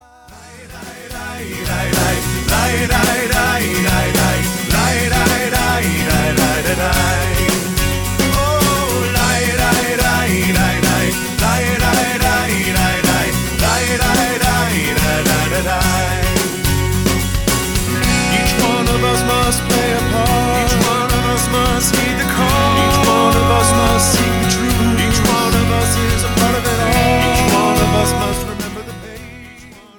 hooky intellectual rock
gorgeous, introspective liturgical modern standards